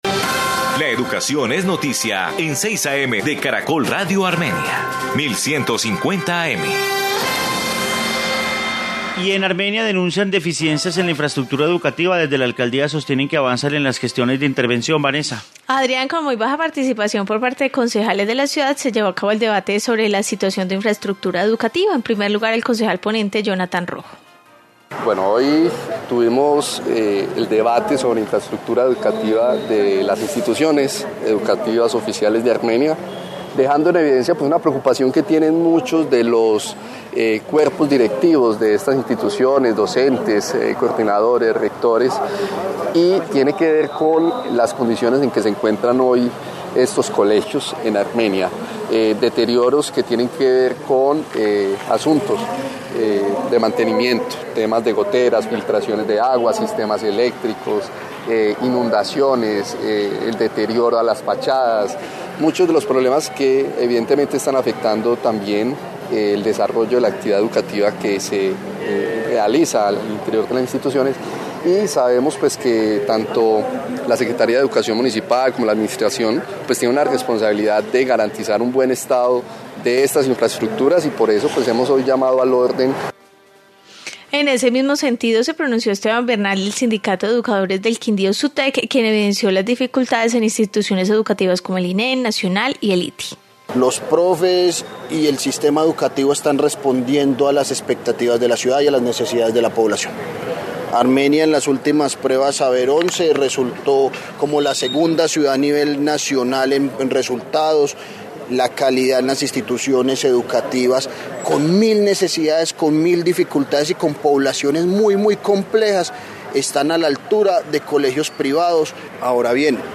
Informe sobre infraestructura educativa